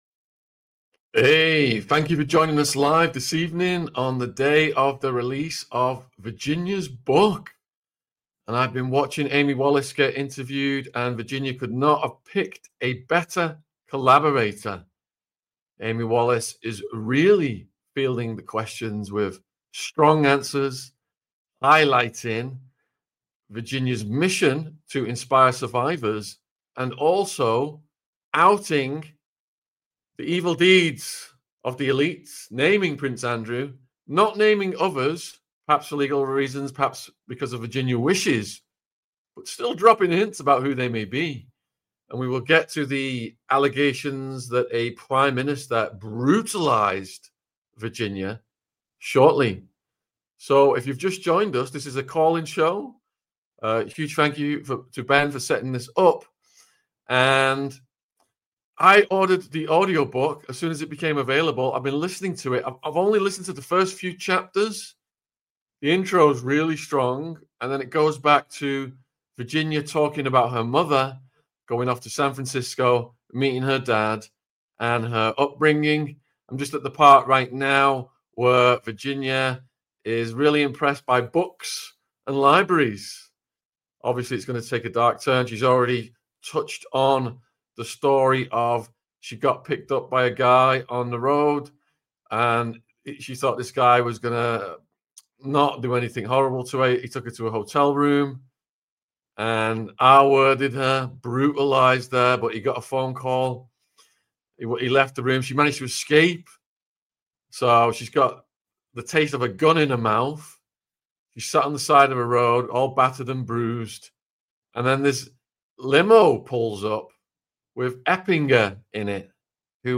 Virginia Guiffre's Book EXPOSES Who? Call in Show Prince Andrew Ehud Barak Bill Clinton | AU 495